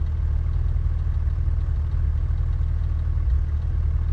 rr3-assets/files/.depot/audio/Vehicles/f6_02/f6_02_idle.wav
f6_02_idle.wav